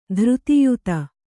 ♪ dhřti yuta